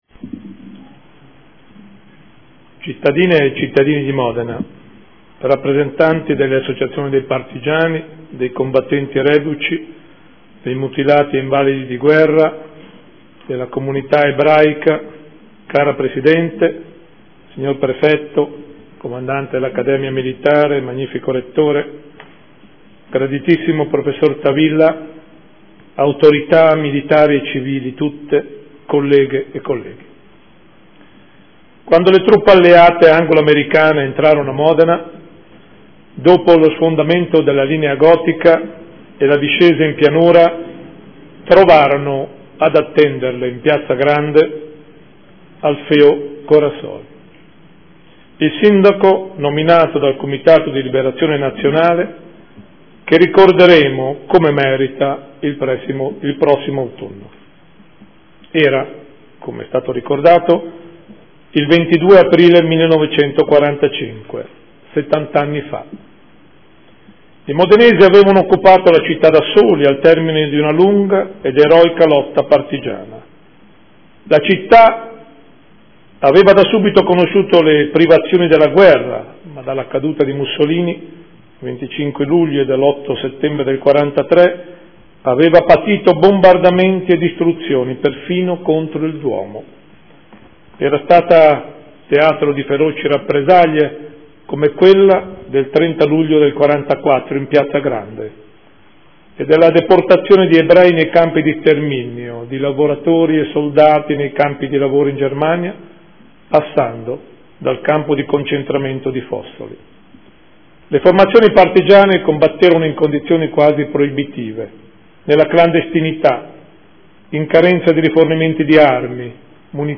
Seduta del 22 aprile. Intervento del Sindaco in ricordo del 70° anniversario della Liberazione